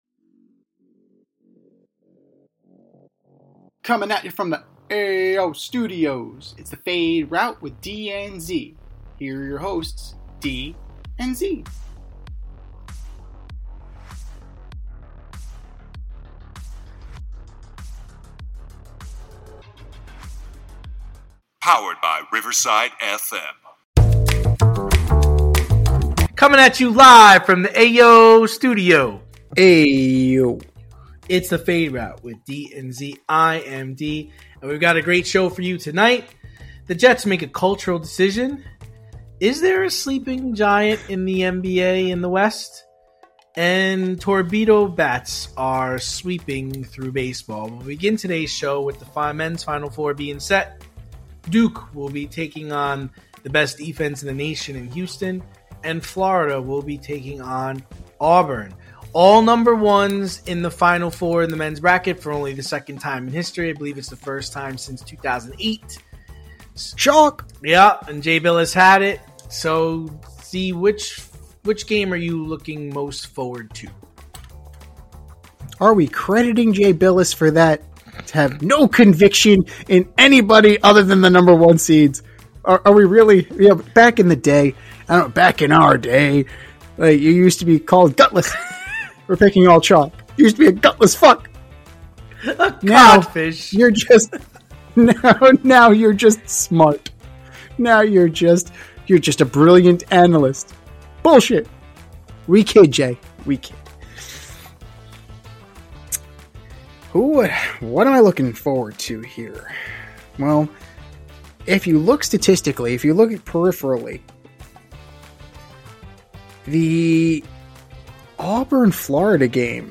Two longtime friends and sports fanatics breaking down the latest news of the week with humor and a New York edge.